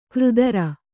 ♪ křdara